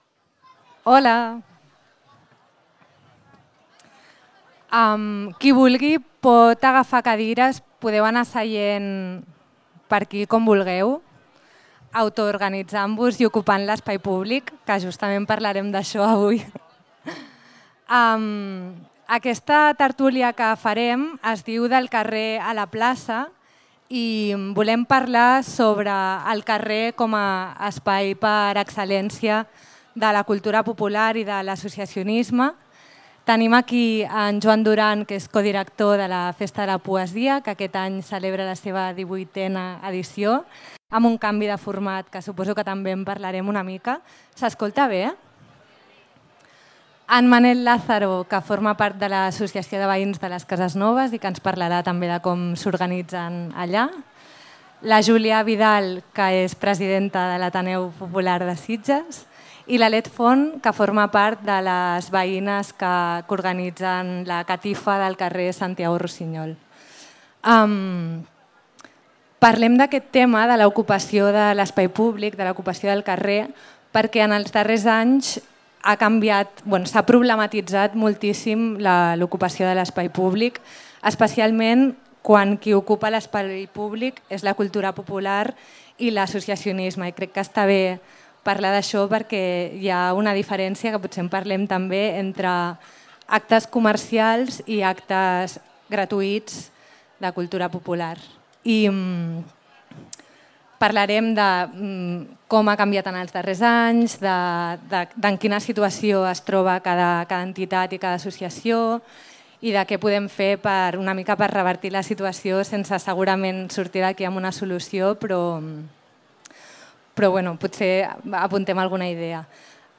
Les entitats i l’ocupació de l’espai públic a debat a ‘La plaça’
En el nou espai habilitat per la comissió, on s'estan realitzant la majoria d'activitats prèvies a la Festa Major, ahir es va parlar, sobretot, de les dificultats de moltes entitats a l'hora de poder desenvolupar activitats a l'espai públic, en un context en el qual, paradoxalment, l'espai públic sovint és ocupat per negocis privats.